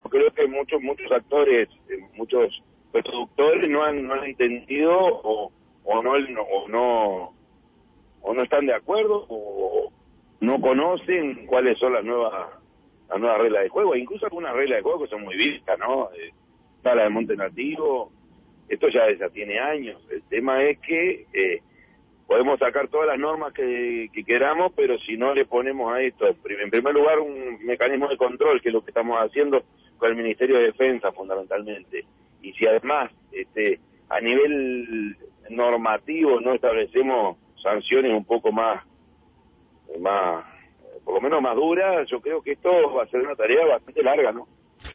El intendente canario Yamandú Orsi, explicó a El Espectador por qué aún sucede este tipo de hechos, a pesar de las disposiciones del gobierno nacinal en cuanto al cuidado de los recursos naturales, fundamentalmente sobre la cuenca del río Santa Lucía.